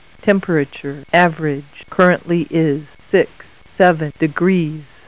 Weather Word connects to a standard telephone line, can run on batteries, and answers the phone with a pleasing female voice
Typical Telephone Answer: